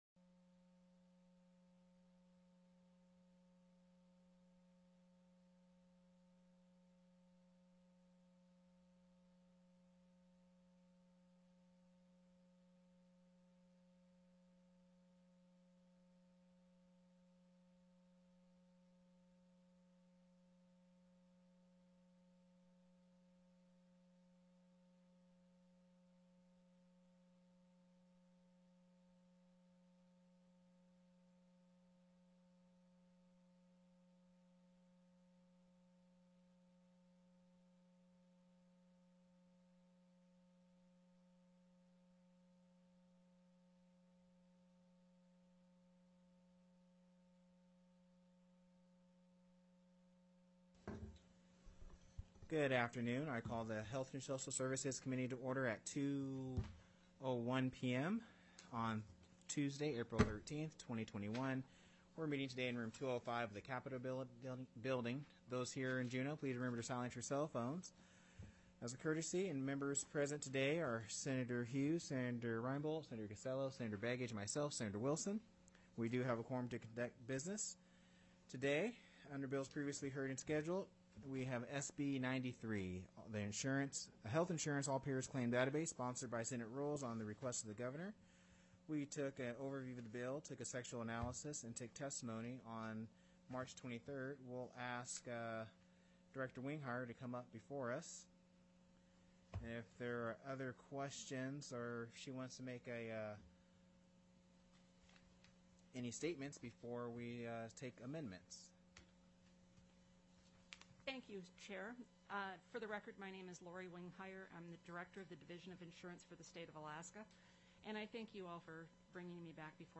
The audio recordings are captured by our records offices as the official record of the meeting and will have more accurate timestamps.
ALL-PAYER CLAIMS DATABASE TELECONFERENCED Moved CSSB 93(HSS) Out of Committee + Bills Previously Heard/Scheduled TELECONFERENCED pdf txt SB 93 -HEALTH INS.